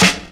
0208 DR.LOOP.wav